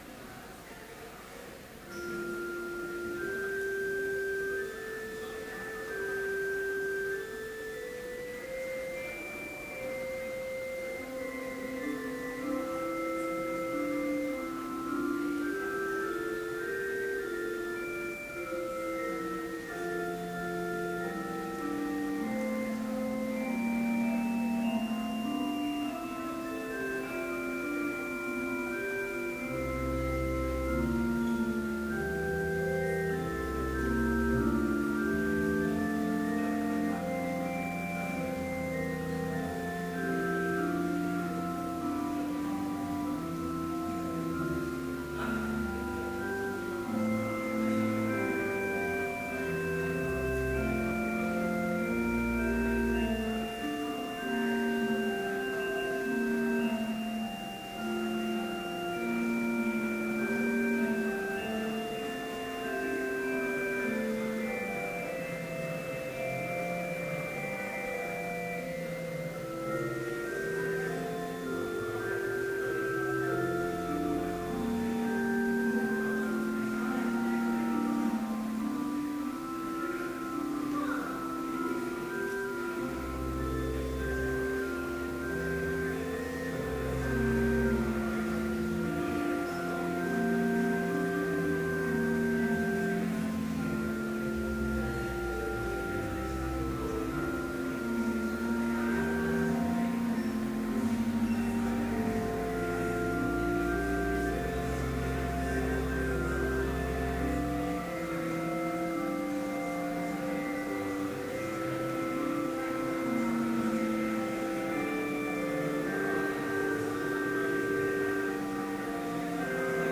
Complete service audio for Chapel - April 22, 2013